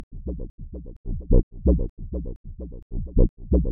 Bass line Free sound effects and audio clips
Original creative-commons licensed sounds for DJ's and music producers, recorded with high quality studio microphones.